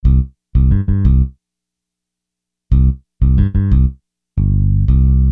Hiphop music bass loops 4
Hiphop music bass loop - 90bpm 70